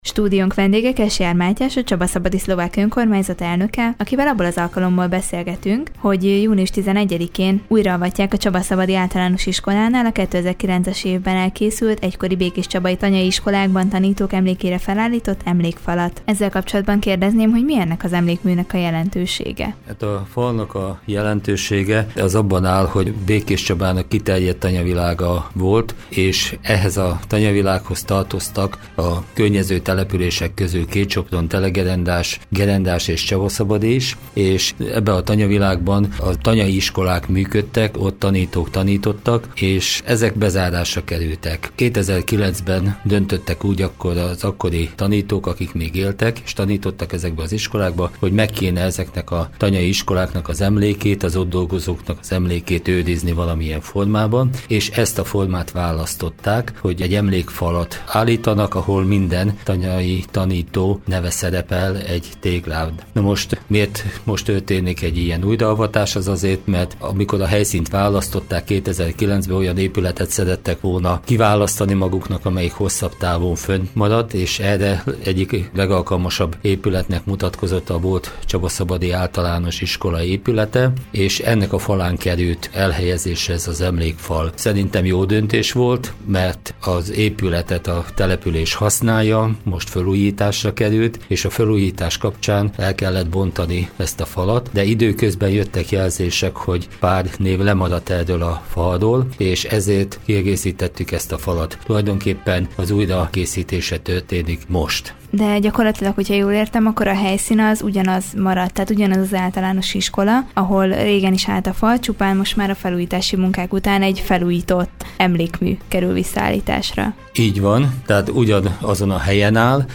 Kesjár Mátyás, a Csabaszabadi Szlovák Önkormányzat elnöke volt a Körös Hírcentrum stúdiójának vendége.